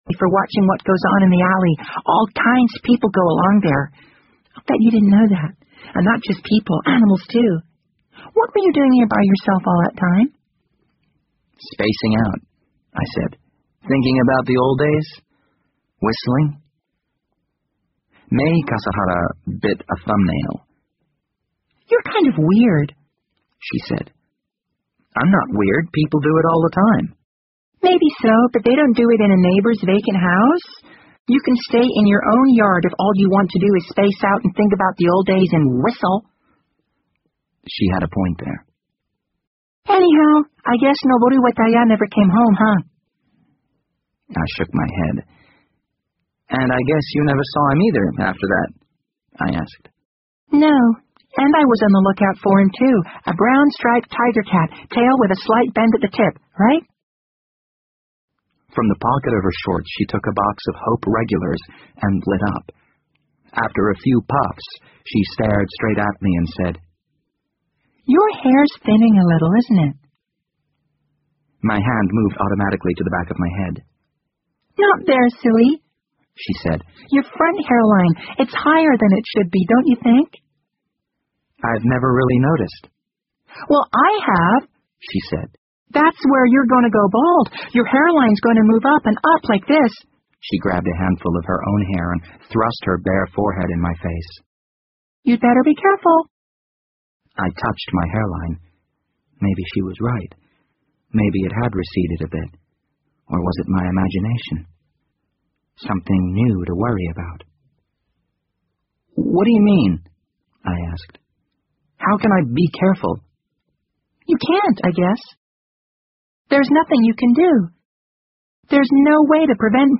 BBC英文广播剧在线听 The Wind Up Bird 31 听力文件下载—在线英语听力室